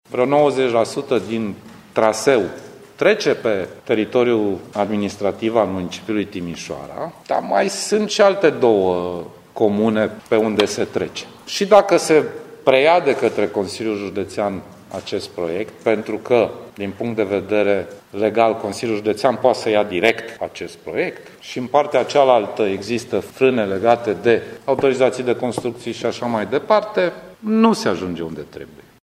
În altă ordine de idei, Sorin Grindeanu, așteaptă ca Primăria Timișoara și Consiliul Județean să se înțeleagă cu privire la preluarea monitorizării lucrărilor de la viitoare centură de vest a Timișoarei: